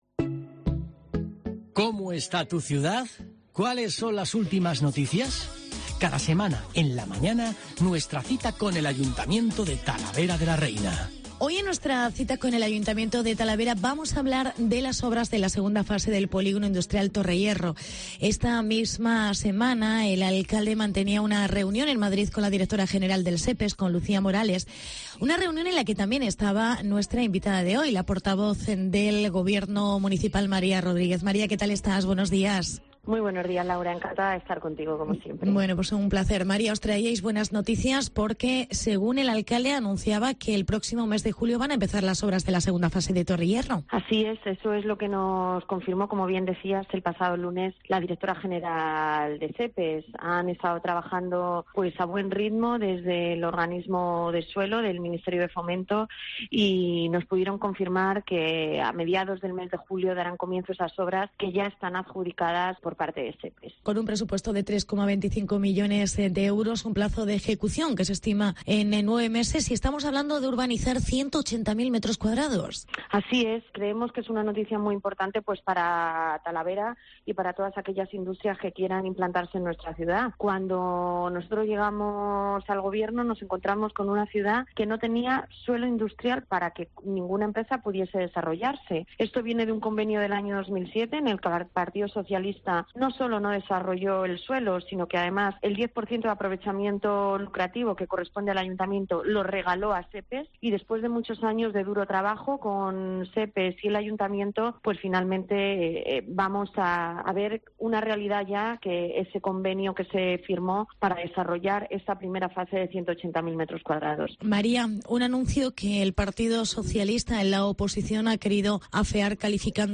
Entrevista con la portavoz del Ayto de Talavera: María Rodríguez